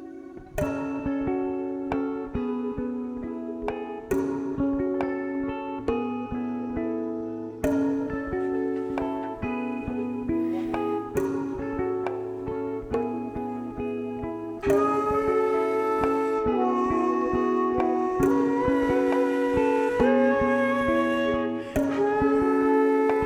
Suprano Sax and Tenor Sax
Guitar; virtual instruments; Drums, Bass, Keys